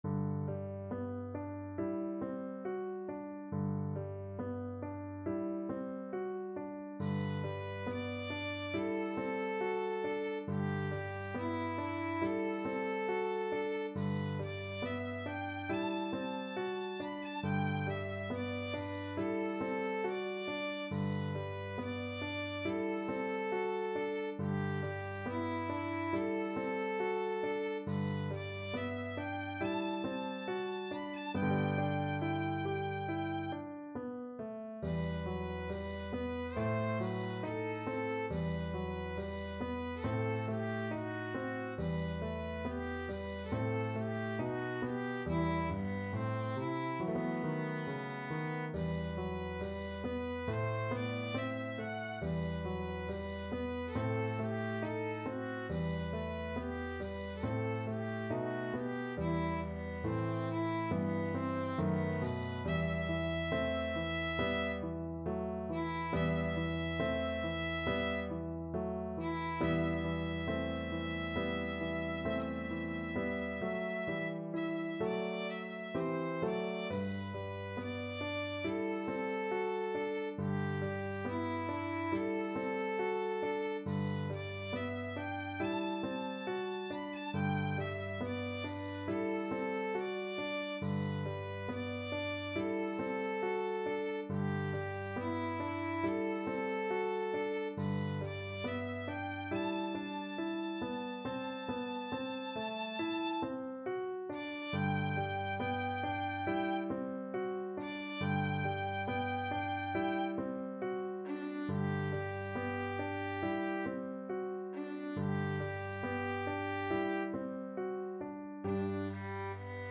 Classical Ilyinsky, Alexander Berceuse from Noure et Anitra Op.13, No.7 Violin version
Violin
4/4 (View more 4/4 Music)
G major (Sounding Pitch) (View more G major Music for Violin )
~ =69 Poco andante
Classical (View more Classical Violin Music)